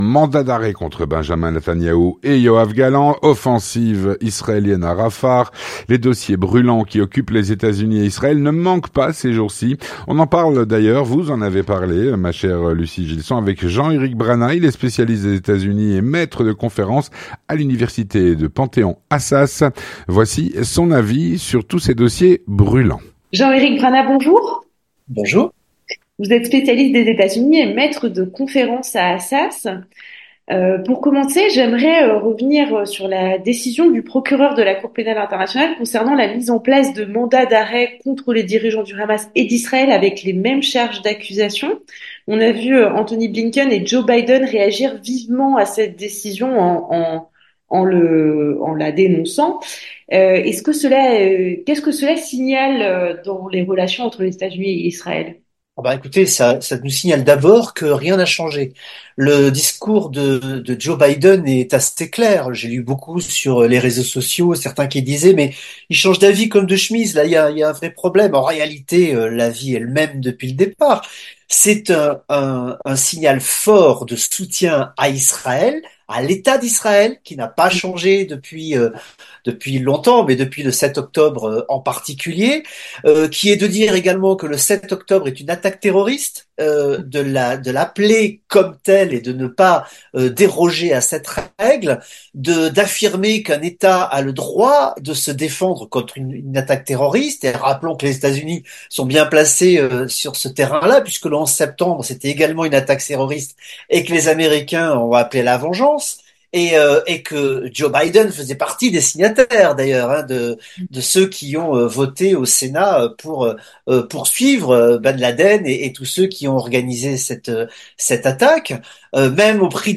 L'entretien DU 18H - Mandats d’arrêt contre Benjamin Netanyahou et Yoav Gallant, offensive israélienne à Rafah, les dossiers brûlants qui occupent les Etats-Unis et Israël ne manquent pas.